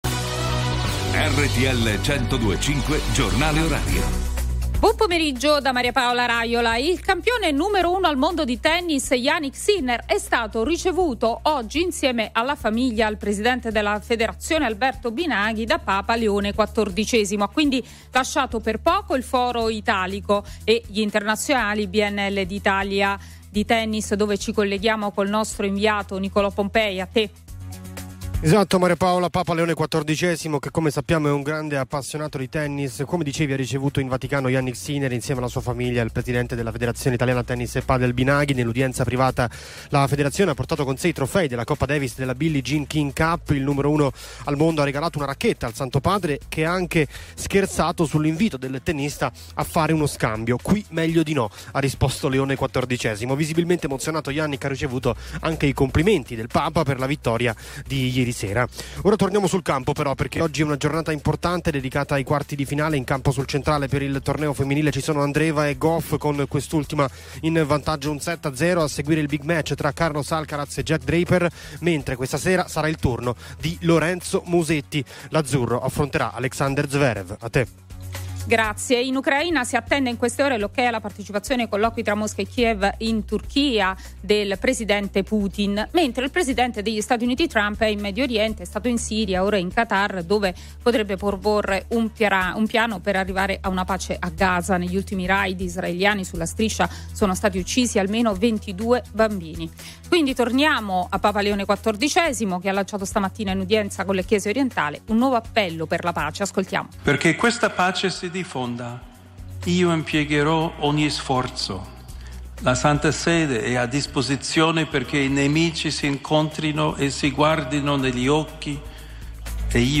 Il giornale orario di RTL 102.5 a cura della redazione giornalistica